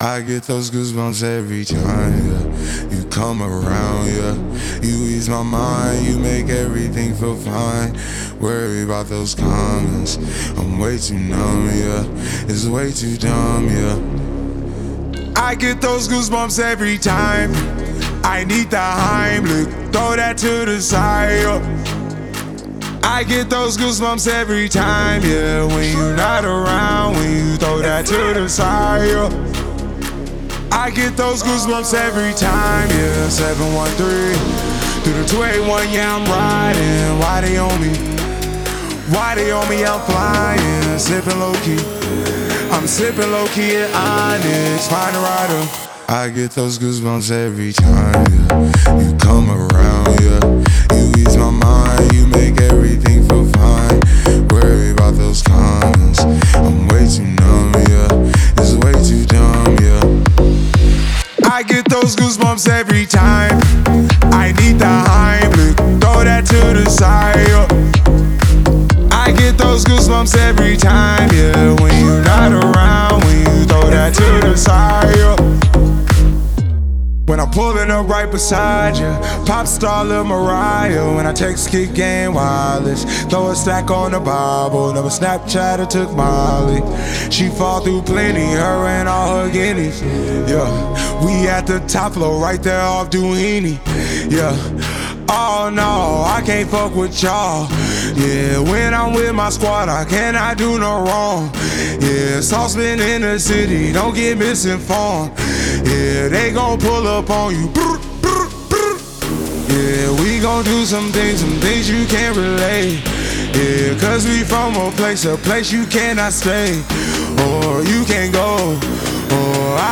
Звучание насыщенное и атмосферное, с элементами трип-хопа.